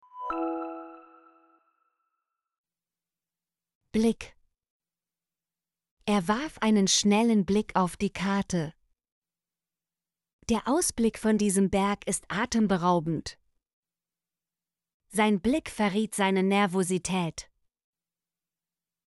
blick - Example Sentences & Pronunciation, German Frequency List